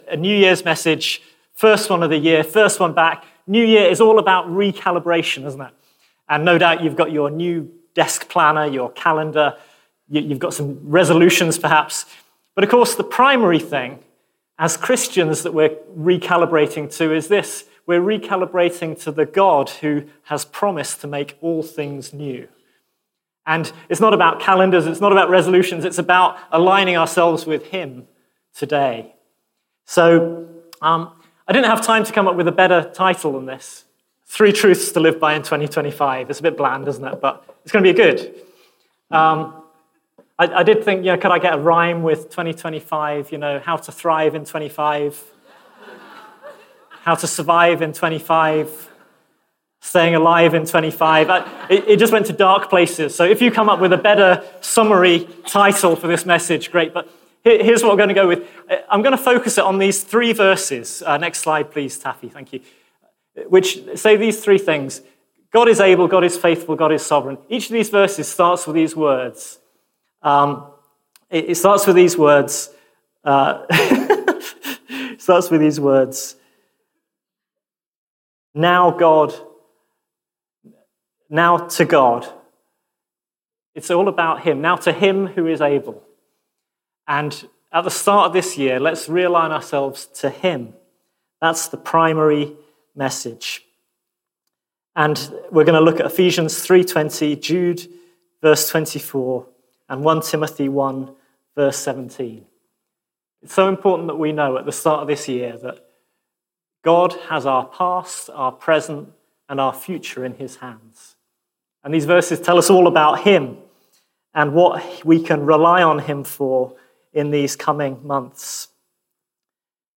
Teaching from the Sunday morning meetings of King's Church Edinburgh.